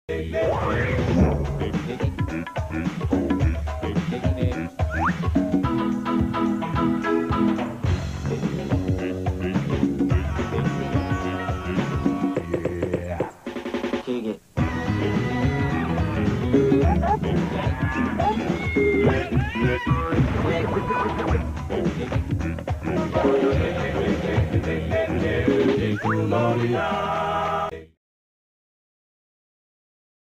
that short jingle somehow felt like recess